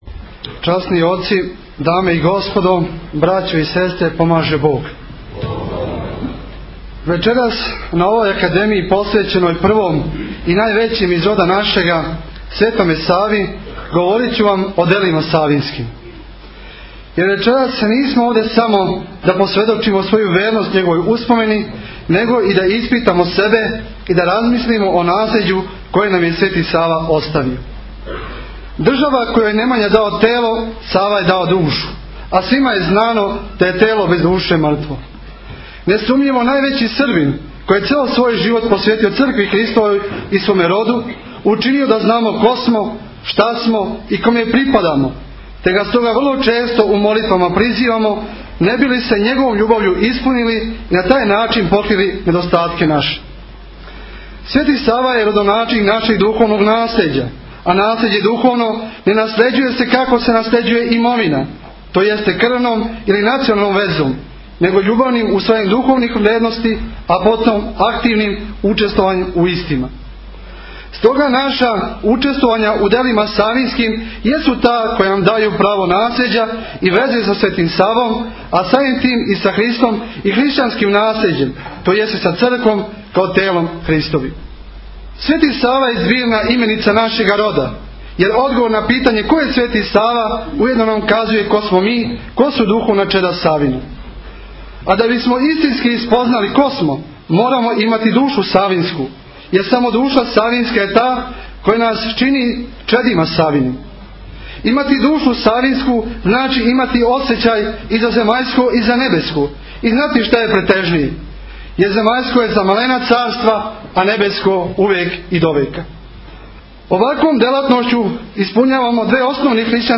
беседом овогодишњу Светосавску академију у сали градског Биоскопа.